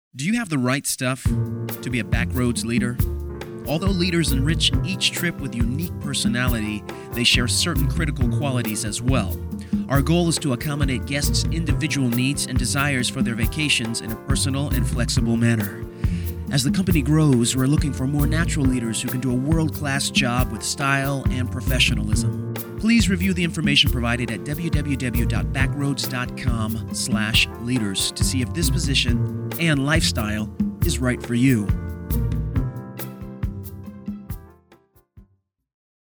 SLightly poetic interpretation. Emotional. NArrative. Perfect Diction. Neutral American Accent.
Sprechprobe: Werbung (Muttersprache):